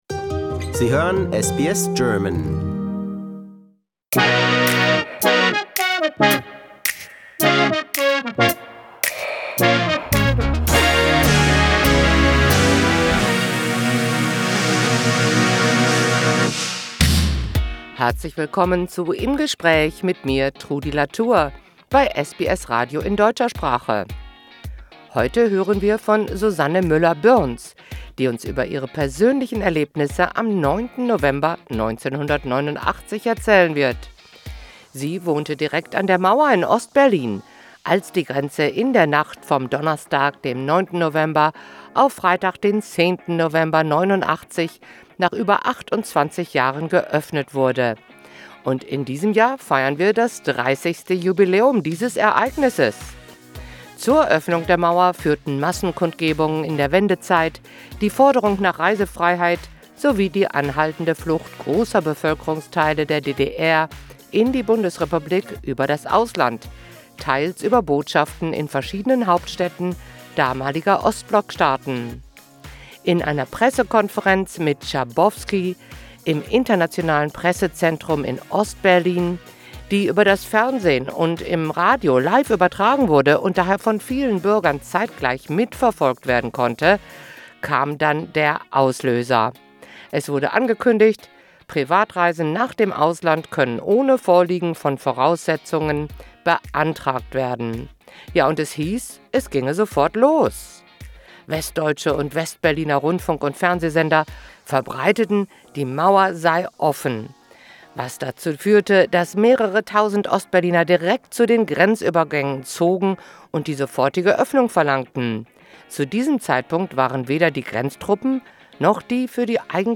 Im Gespräch: Die Nacht in der die Grenze aufgemacht wurde